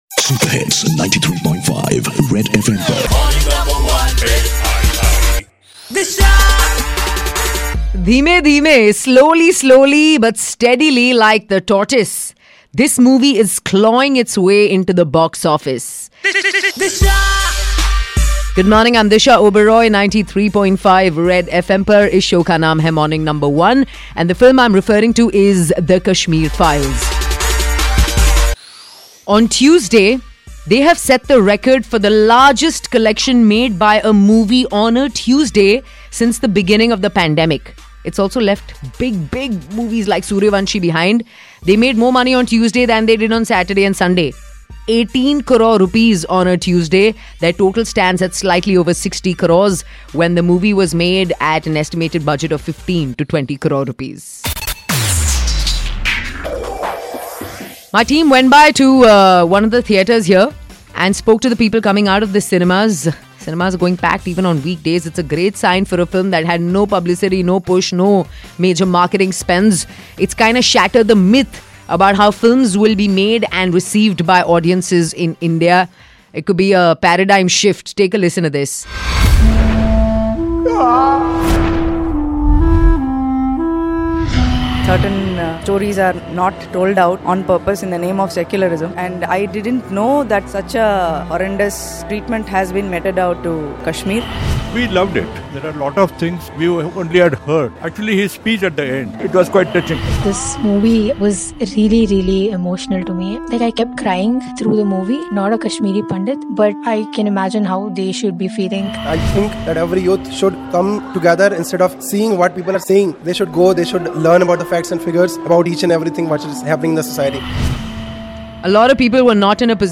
Kashmir files Beats All Bollywood Biggies To Score Biggest Monday Post-Pandemic! Tune in to listen to audience reactions